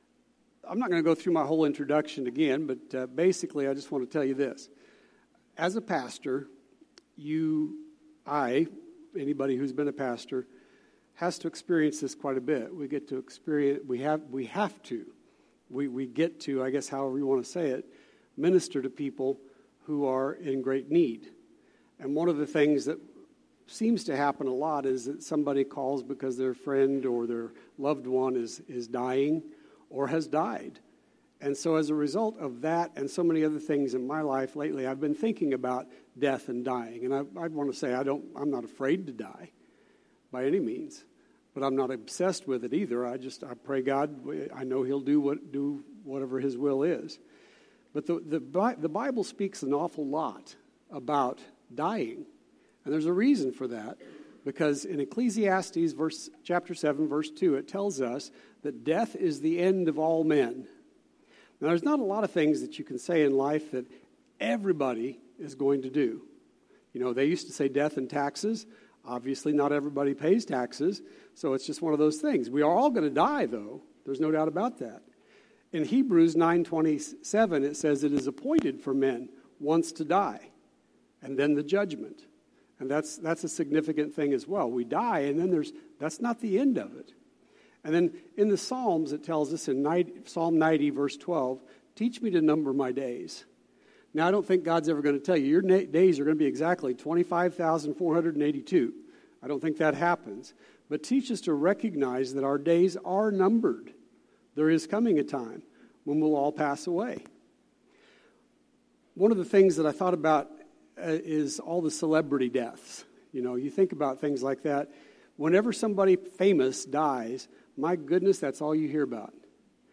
Sermon Archive – Immanuel Baptist Church